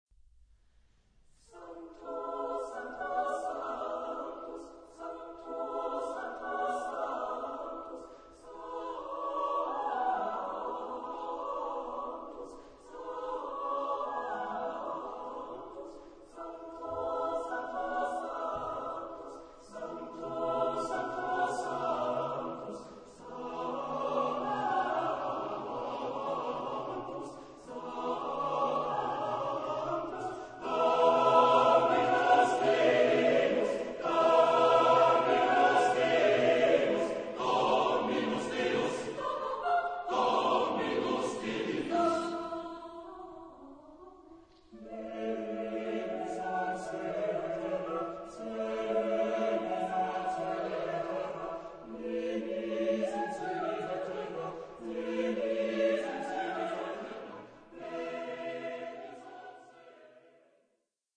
SATB (div) O SSAATTBB (4 voces Coro mixto).
neoclassico.
Misa.